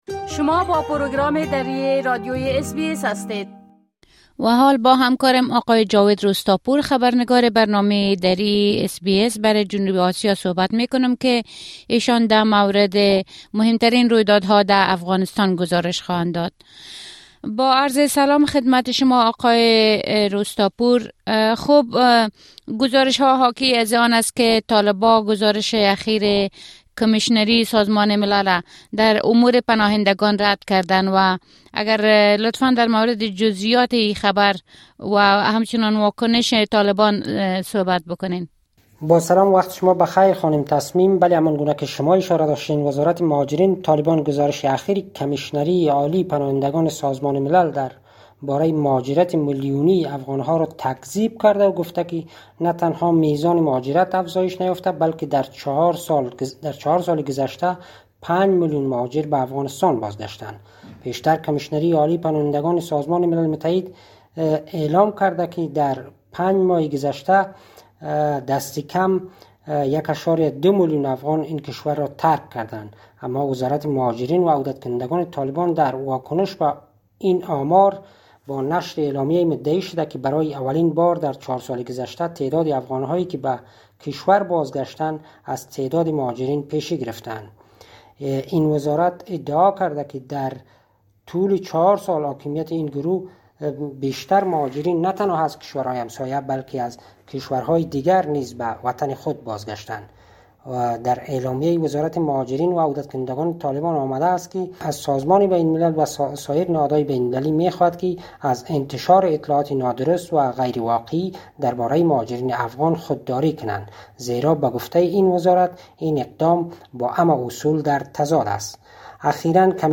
خبرنگار ما برای جنوب آسیا: طالبان گزارش کمیشنری سازمان ملل را در امور مهاجرین رد کرده اند
گزارش كامل خبرنگار ما، به شمول اوضاع امنيتى و تحولات مهم ديگر در افغانستان را در اين‌جا شنيده مى توانيد.